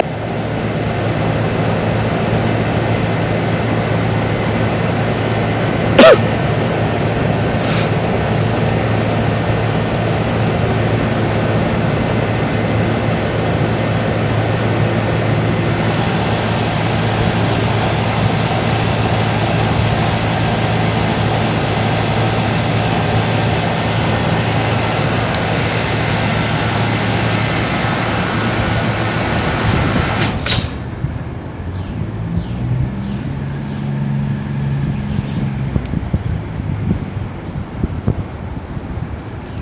Field recording 3
The sidewalk between Lowe and Memorial halls.
Sounds heard: Some sort of heating unit or generator running, then shutting off; also me coughing and sniffling, the sounds of bird calls, and of footsteps.
sidewalkmp3.mp3